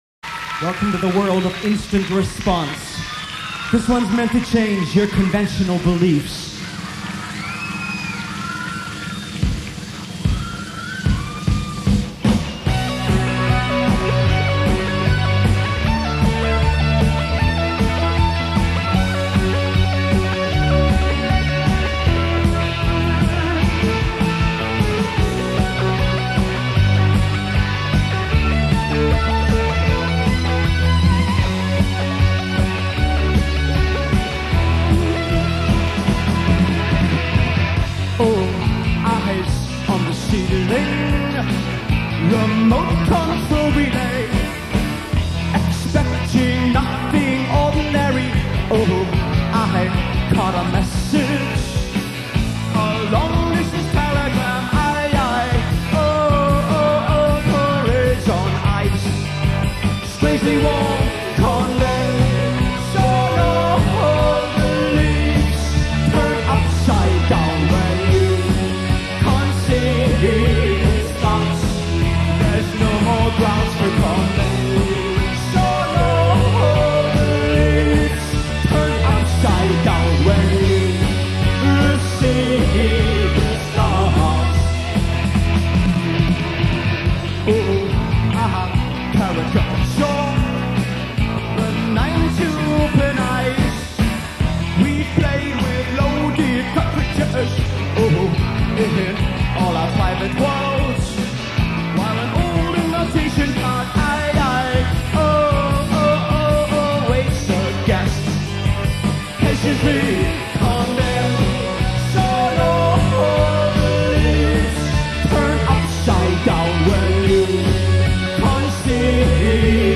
guitar
bass
keyboards
drums
Source : Radio Broadcast - Cassette